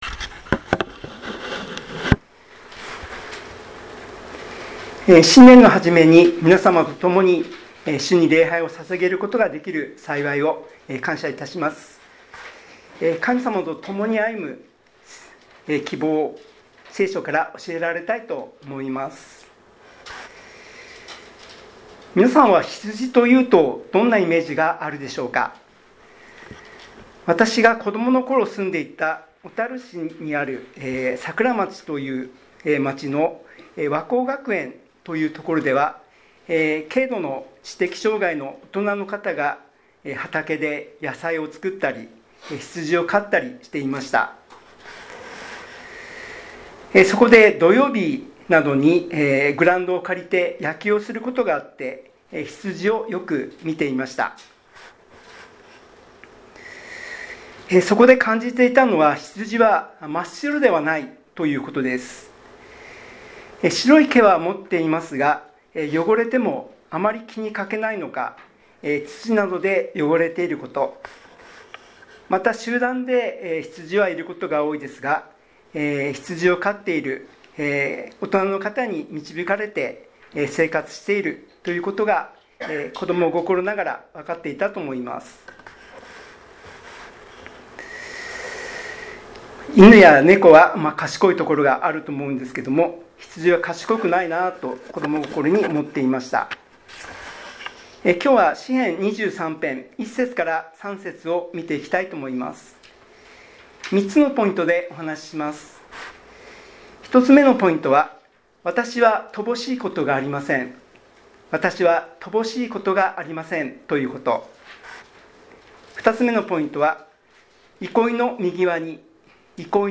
2026年1月1日元日礼拝
礼拝メッセージ